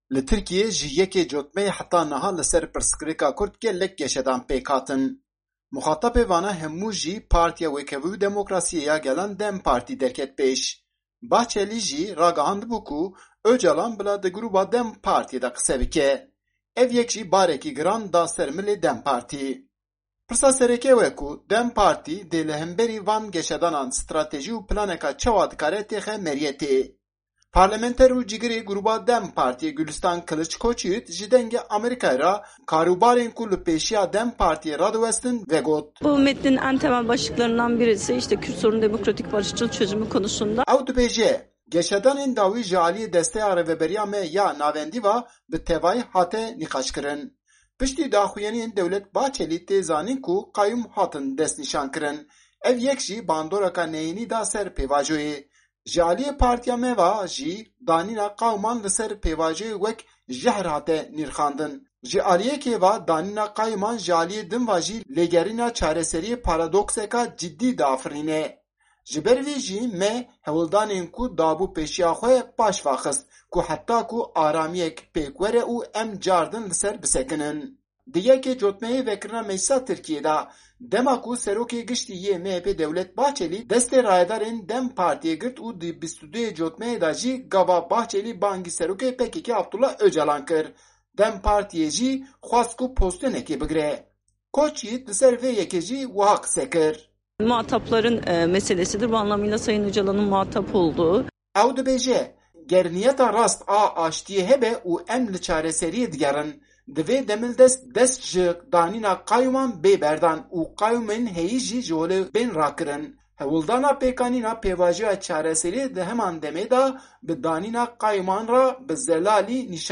لە ئانکەرە